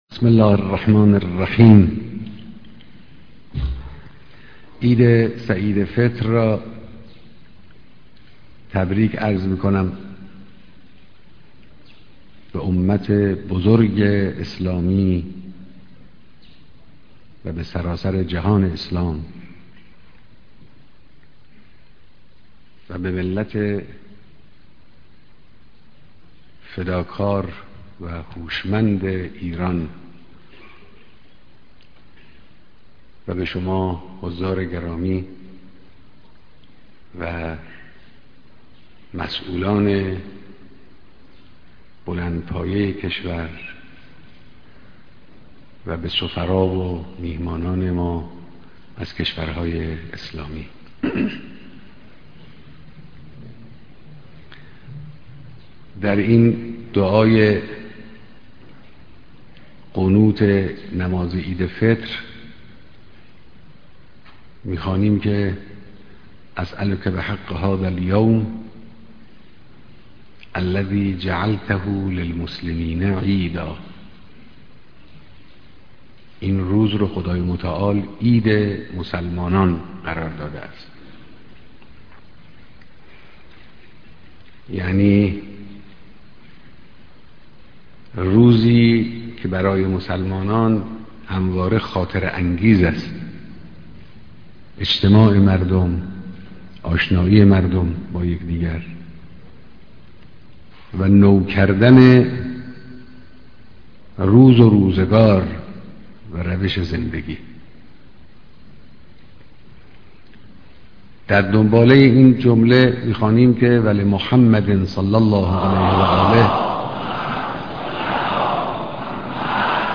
بيانات در ديدار مسؤولان نظام و سفراى كشورهاى اسلامى